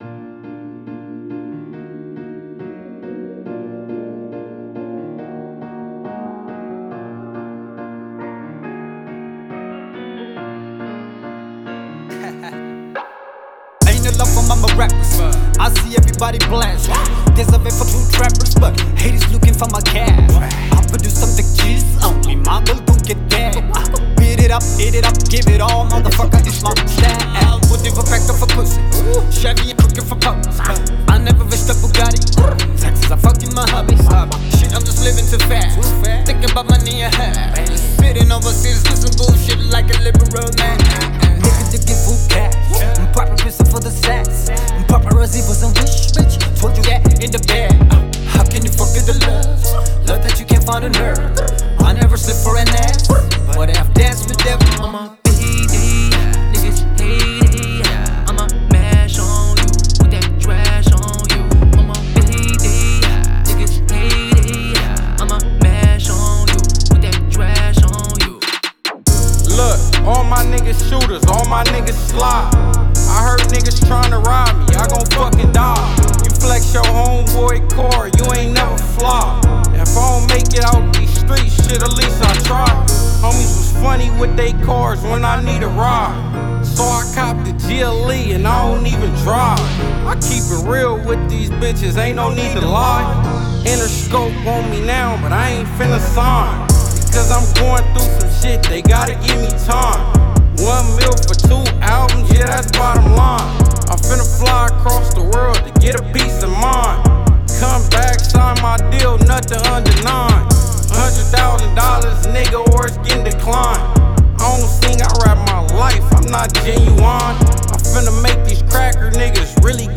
Robot Piano